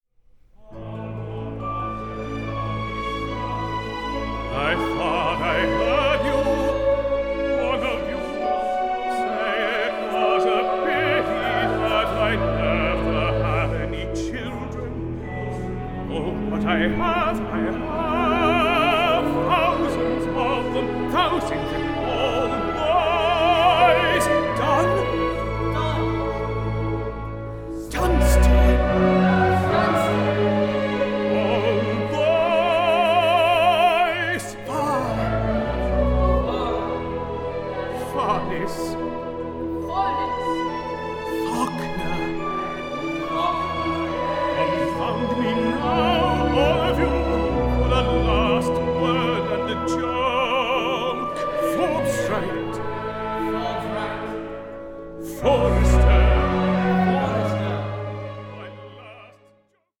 A FEEL-GOOD OPERA ABOUTTHE TEACHER WE ALL WISH WED HAD
new studio recording